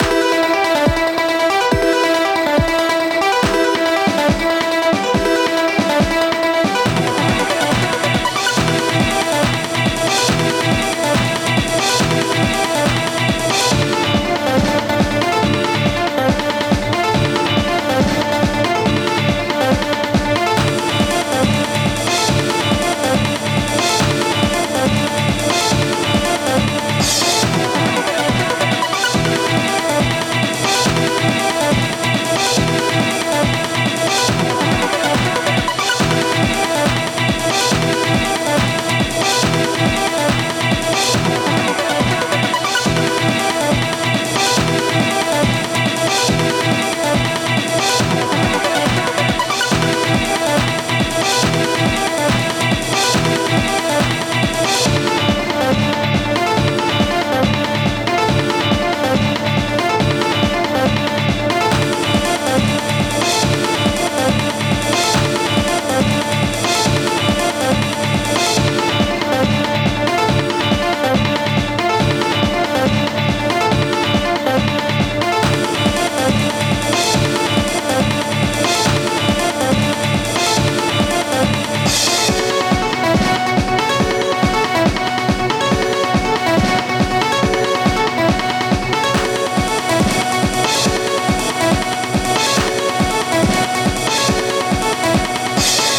サイバーパンクな戦闘向けBGMです！
ループ：◎
BPM：140 キー：Em ジャンル：みらい 楽器：シンセサイザー、ギター